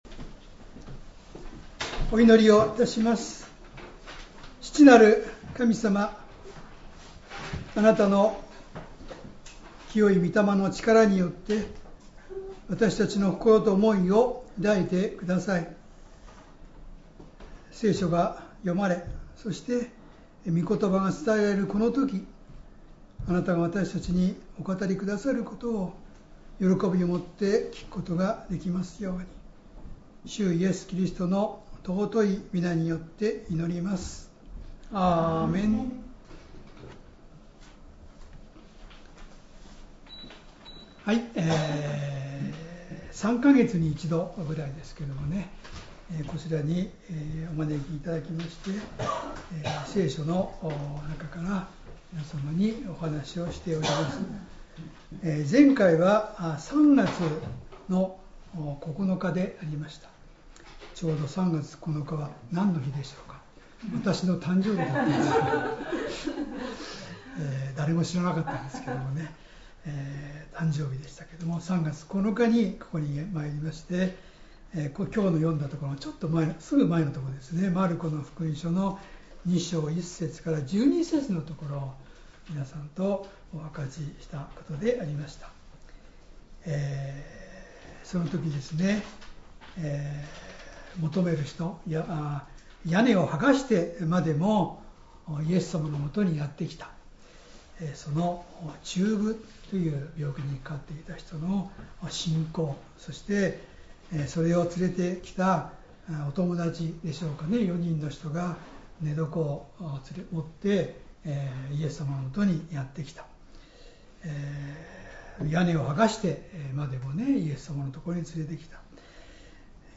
Sermon
2025年 6月8日 主日礼拝 説教 罪びとを招くため マルコの福音書 2章 13-17節 2:13 イエスはまた湖のほとりへ出て行かれた。